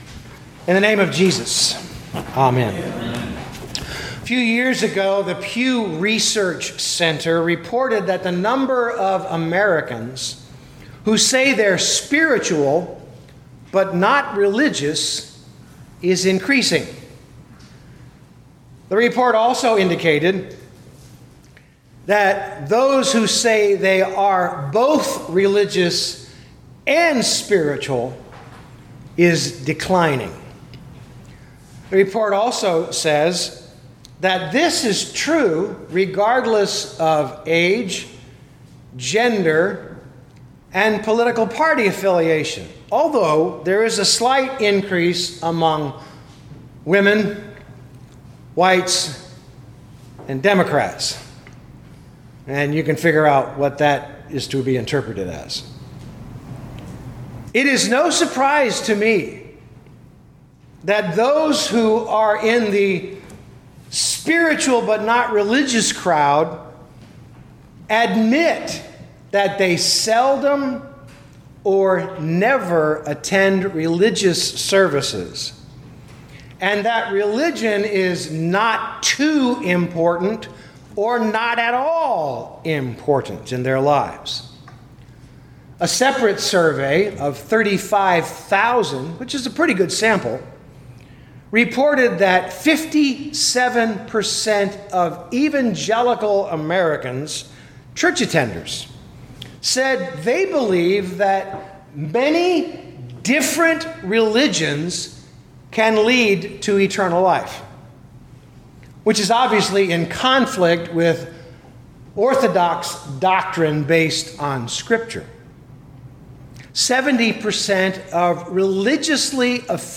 2022 John 14:23-31 Listen to the sermon with the player below, or, download the audio.